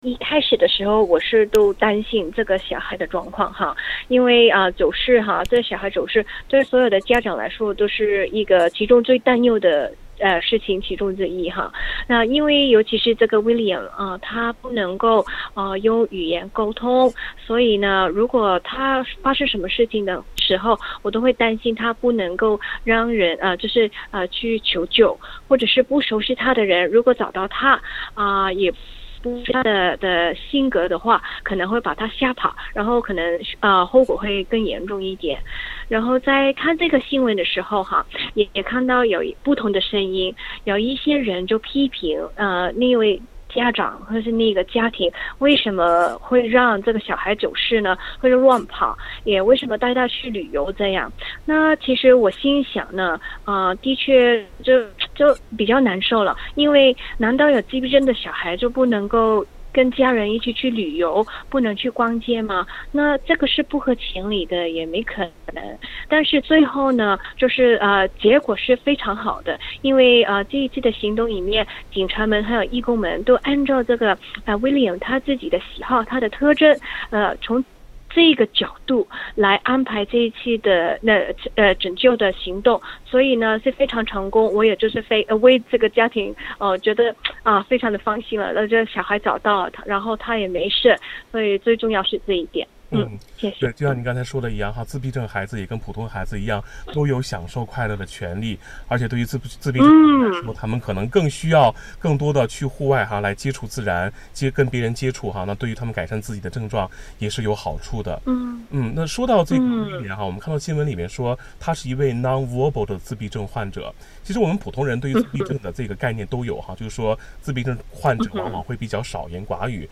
点击封面图片，收听采访录音。